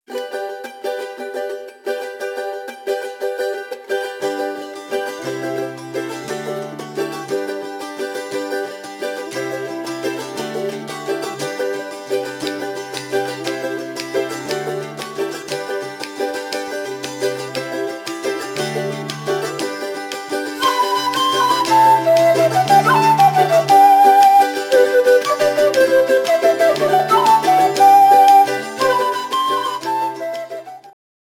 （インストゥルメンタル：ボリビア伝承曲）